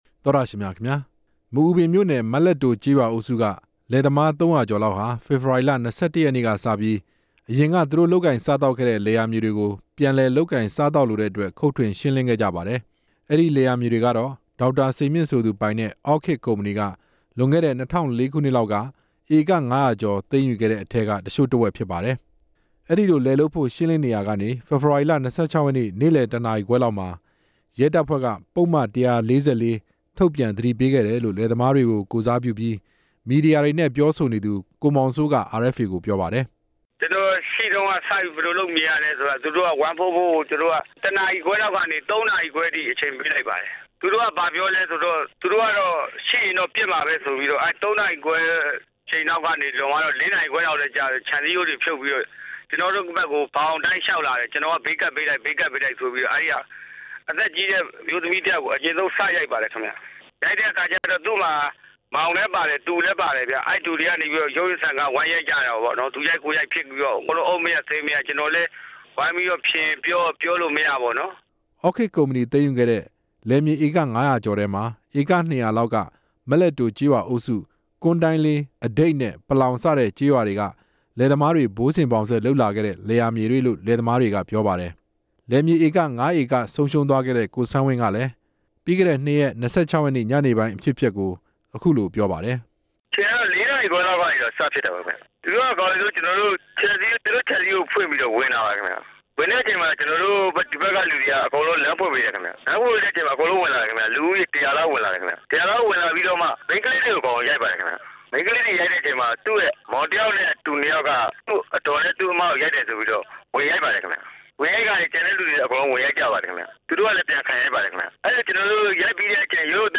ဒေသခံရွာသားတွေနဲ့ မေးမြန်းချက်